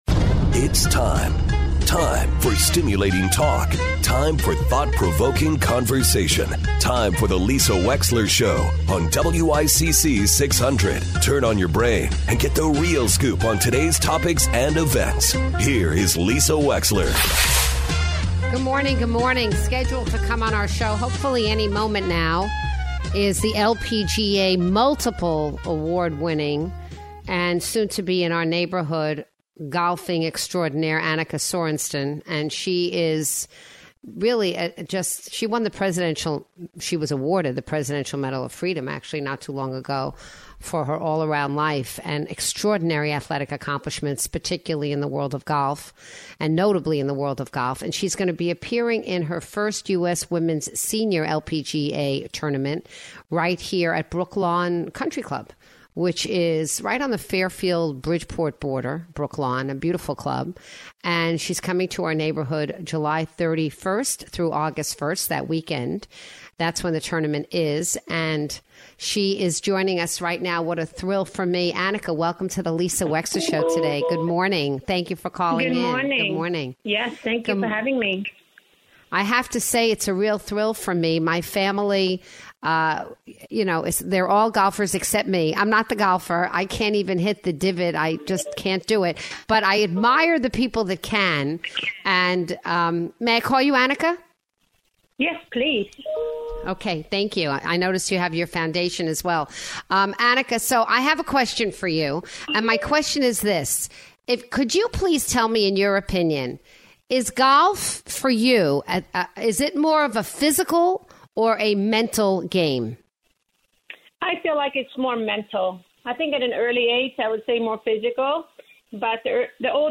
LPGA Champion Annika Sorenstam joins the show to chat about her upcoming visit to CT and about her journey through women's professional golf.